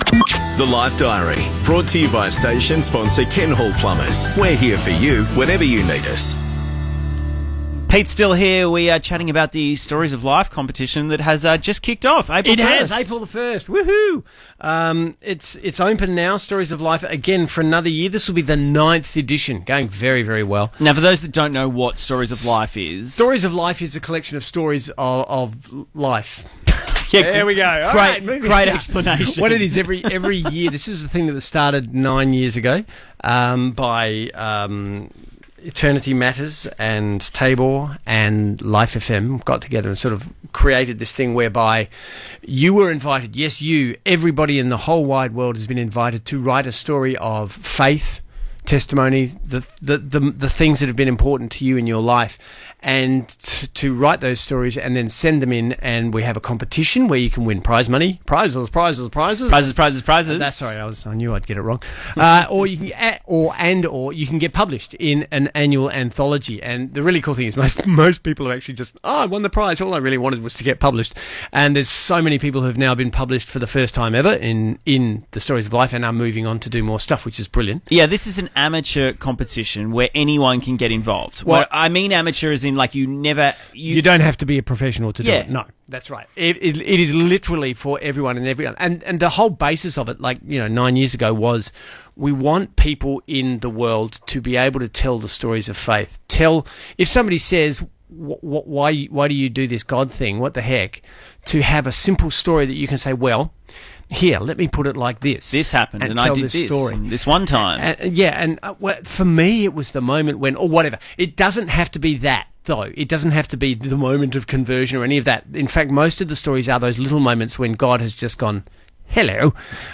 Interview Excerpt